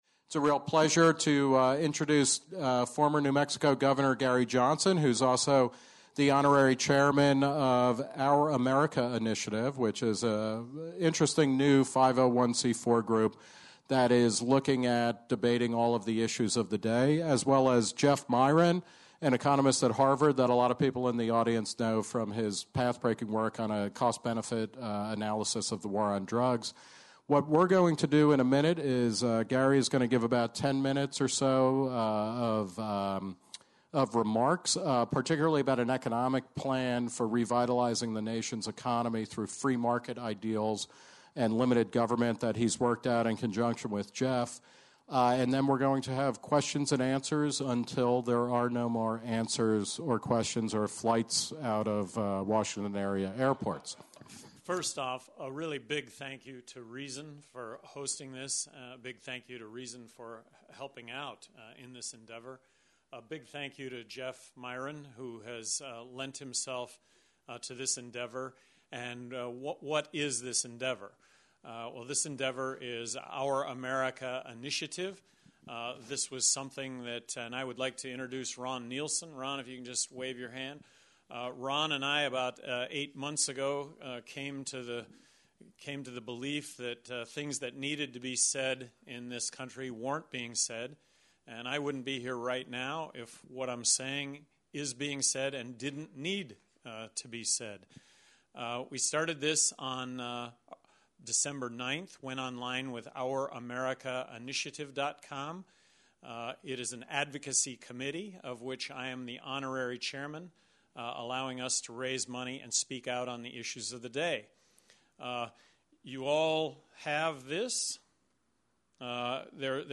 moderates audience Q&A